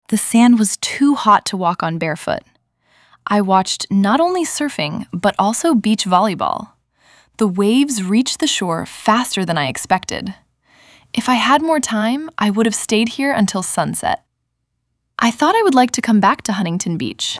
アメリカ人女性